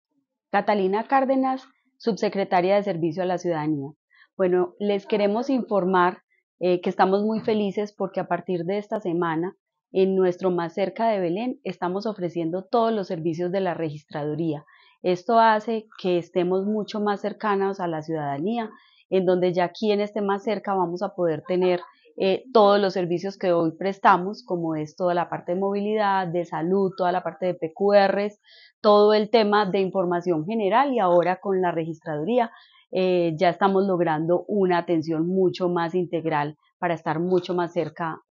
Palabras de Catalina Maria Cárdenas, subsecretaria de Servicio a la Ciudadanía